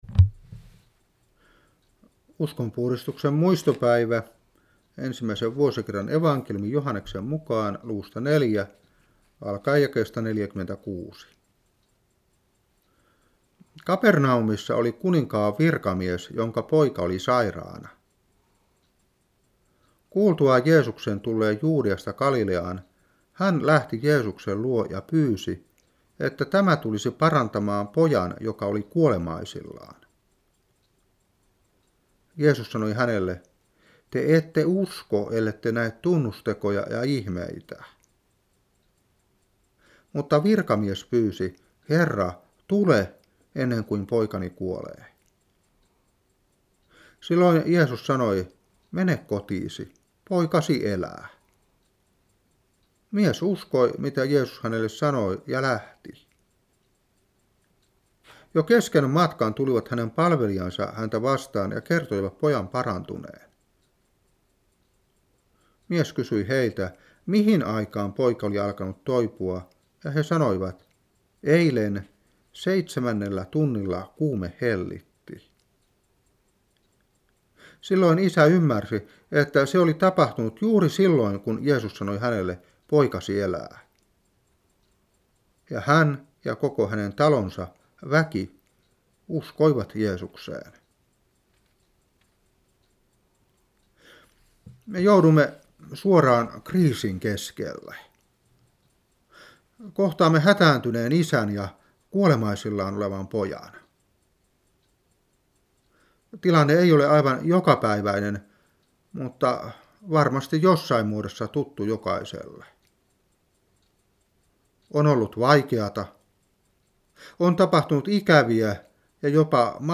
Saarna 2001-11.